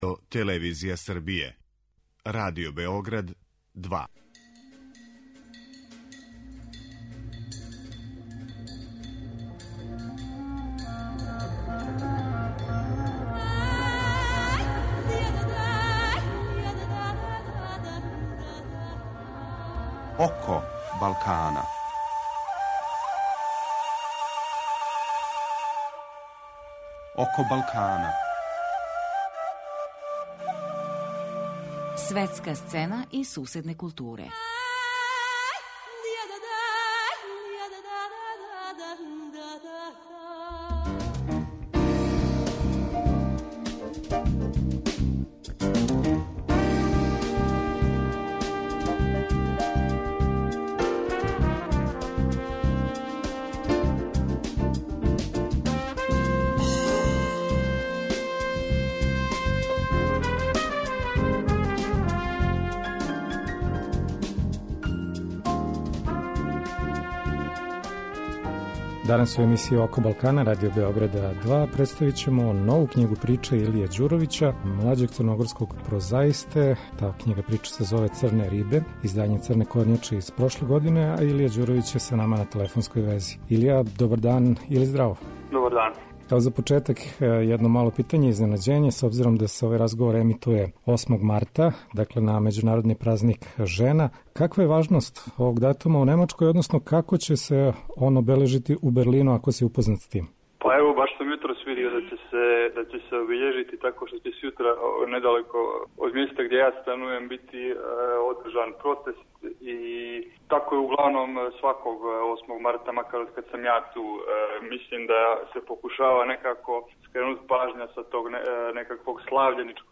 Разговори о регионалној књижевности